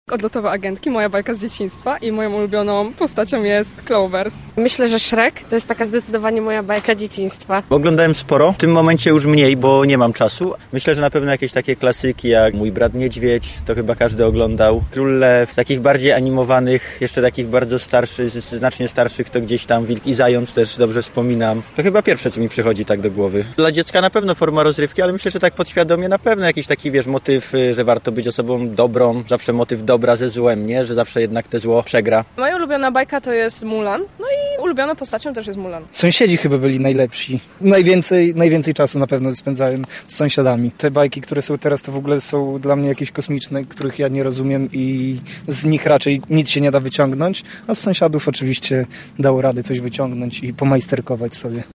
[SONDA] Jacy są nasi ulubieni bohaterowie z bajek?
Zapytaliśmy mieszkańców Lublina, jacy są ich ulubieni animowani bohaterowie:
SONDA